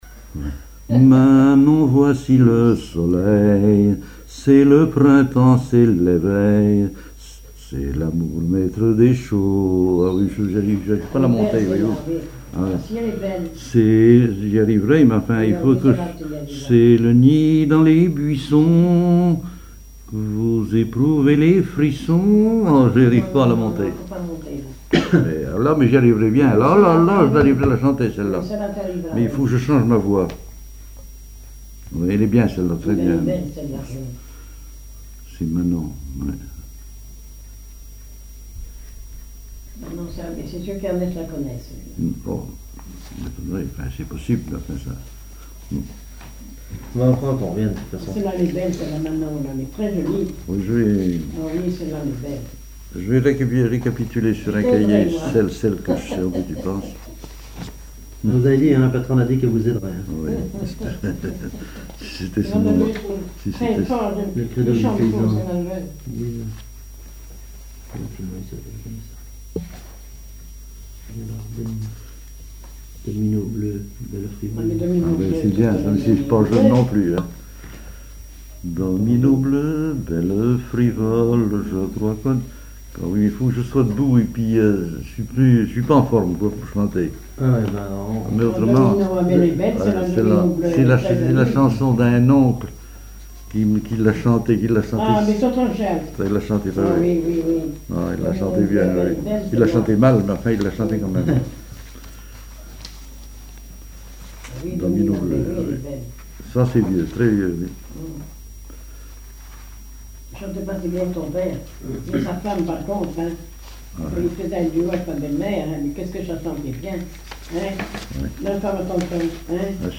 Enquête Arexcpo en Vendée
Catégorie Témoignage